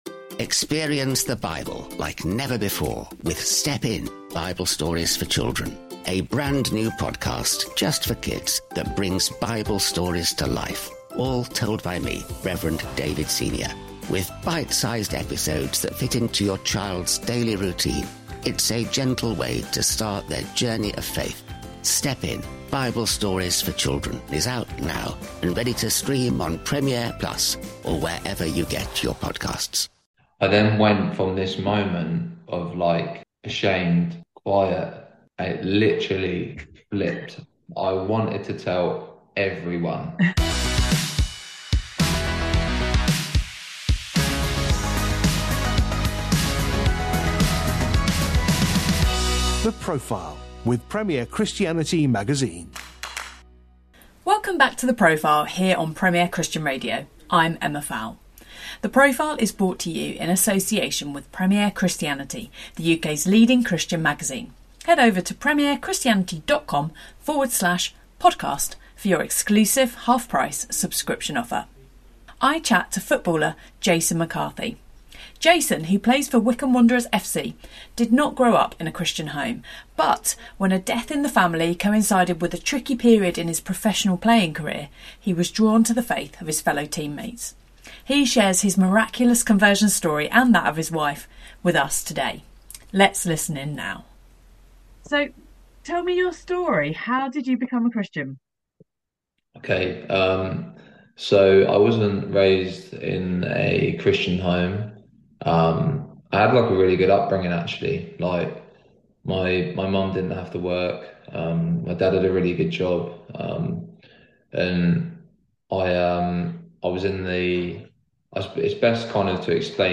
The Profile brings you in-depth interviews to help you learn from the wisdom and experiences of people who have gone all-in for God.